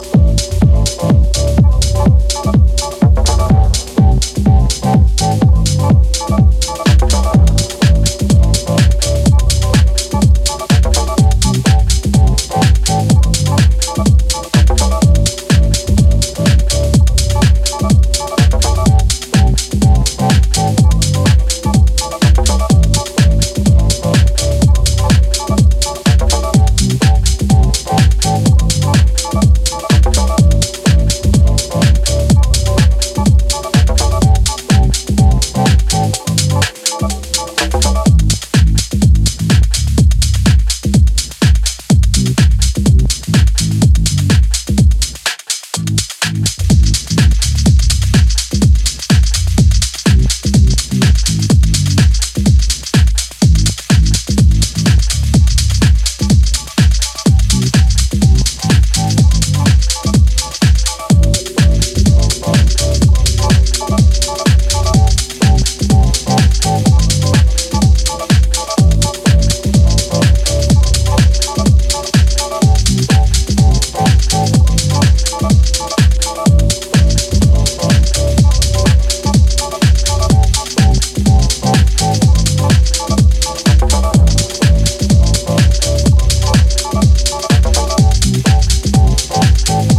straight up house jams, hints of techno, nods to broken beat